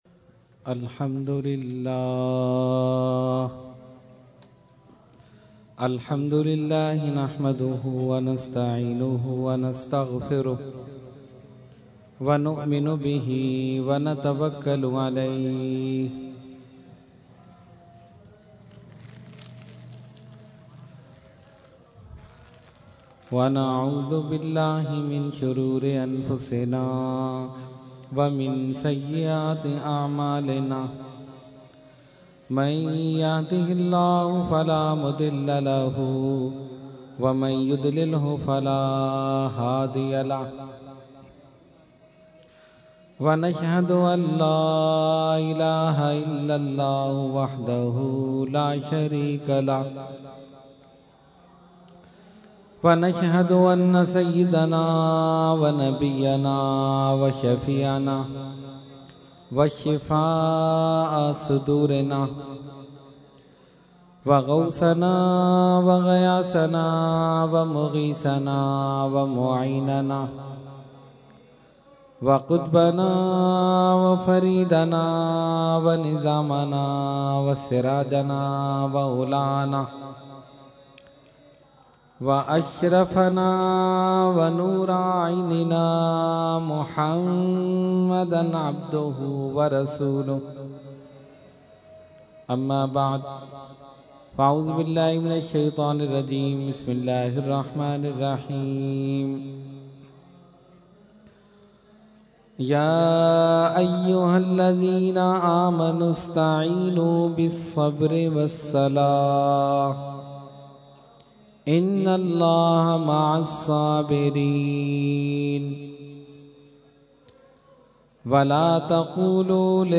Category : Speech | Language : UrduEvent : Muharram 2012